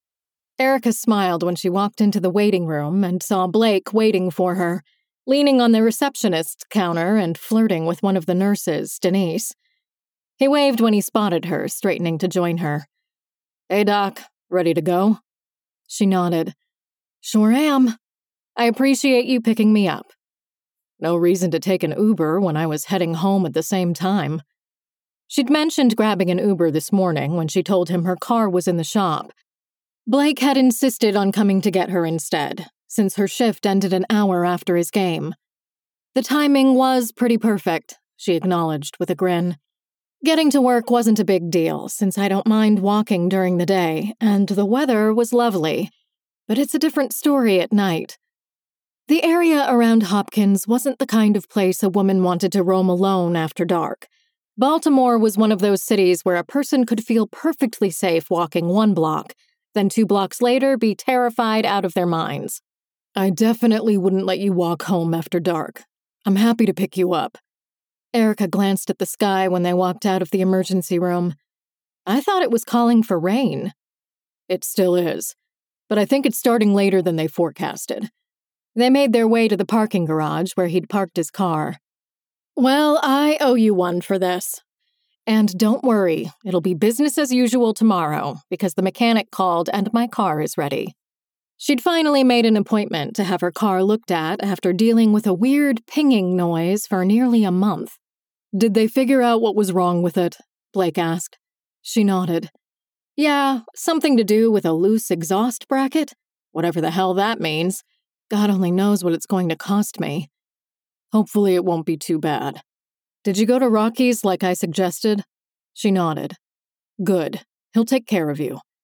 16_PD270_Restraint_FemaleSample.mp3